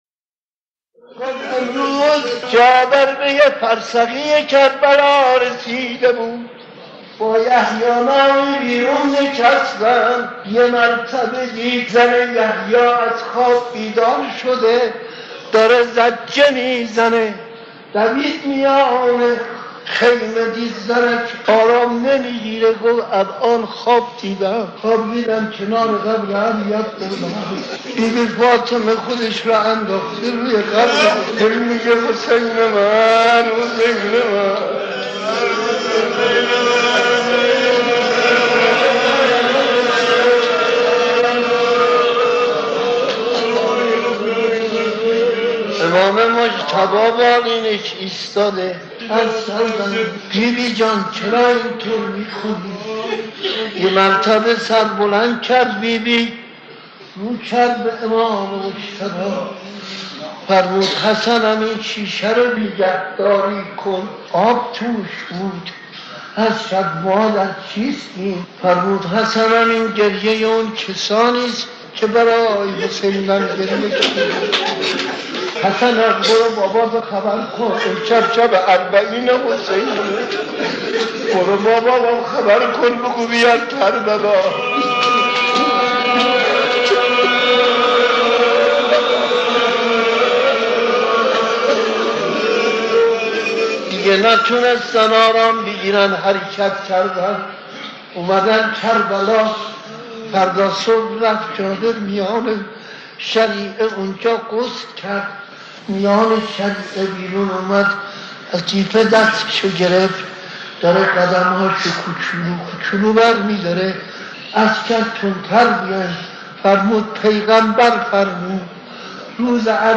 در پرده عشاق، صدای مداحان و مرثیه‌خوانان گذشته تهران قدیم را خواهید شنید که صدا و نفس‌شان شایسته ارتباط دادن مُحب و مَحبوب بوده است.
مصیبت‌خوانی در اربعین و شرح داستان ورود جابر، نخستین زائر تربت سالار شهیدان (ع)